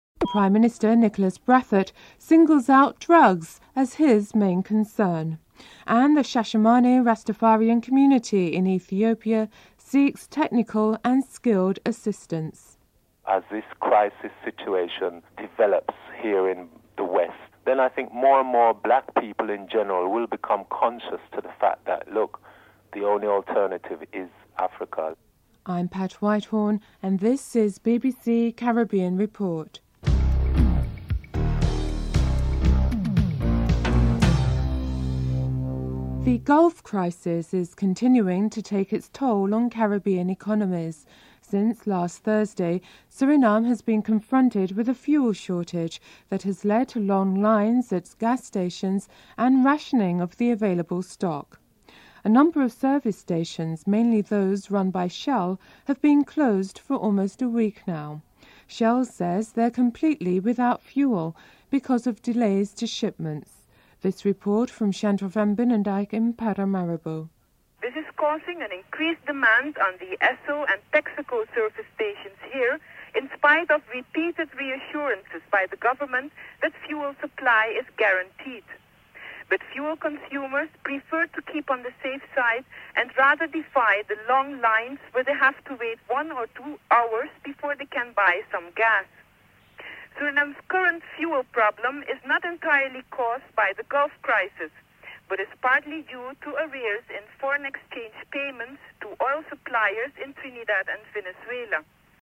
Reports commences during the headlines and ends abruptly.